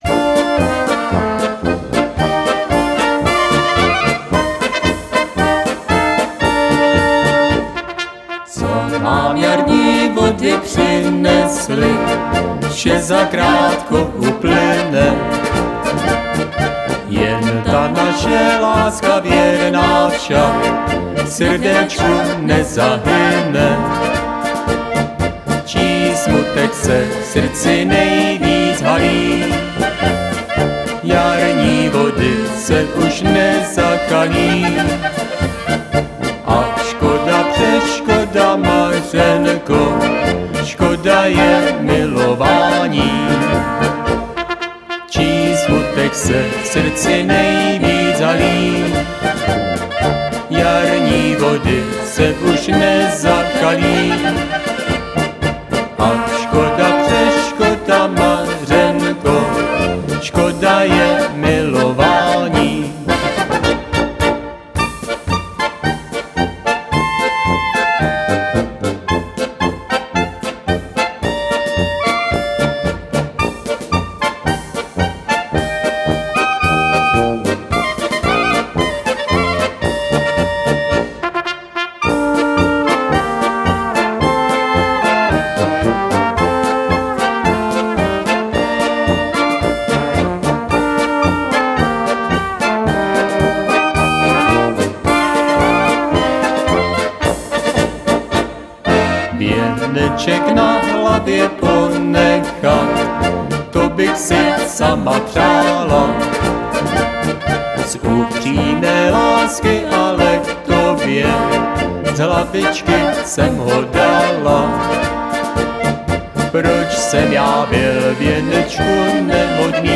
multižánrový orchestr
svatbách i oslavách. 9 muzikantů, zpěvačka